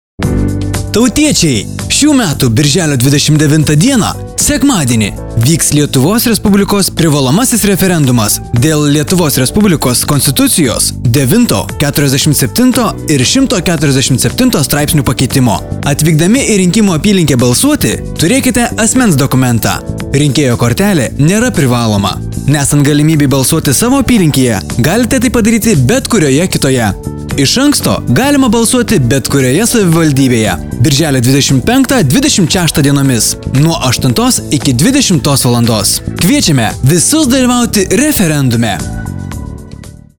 Garsinis įrašas tinkantis RADIJO eterio sklaidai:
Referendumo-informacinis-pranesimas-radijo-stotyse.mp3